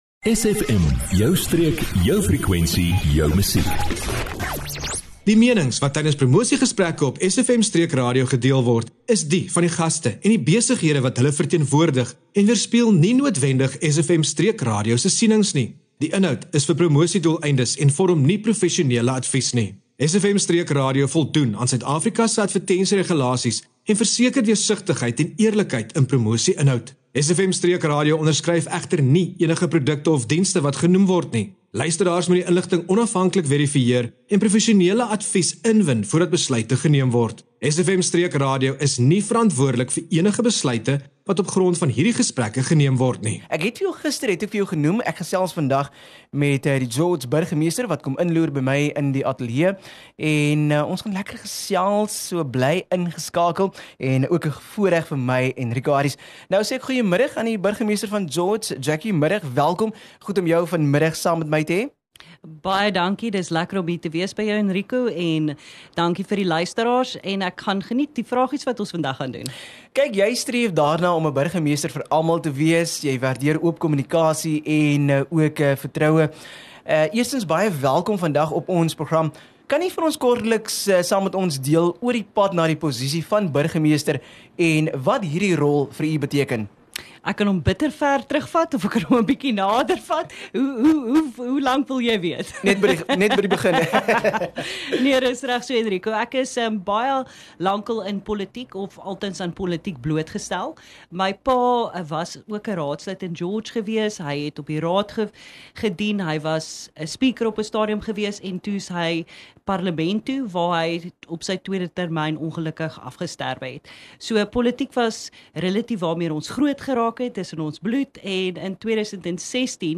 🎙 Eerste Onderhoud met George se Nuwe Burgemeester – Alderman Jackie Von Brandis SFM Streek Radio het die voorreg gehad om die eerste onderhoud te voer met George se nuwe burgemeester, Jackie Von Brandis. Sy het haar planne en visie vir haar termyn gedeel, insluitend strategiese ontwikkelingsprojekte en maniere om George se unieke gemeenskappe te ondersteun.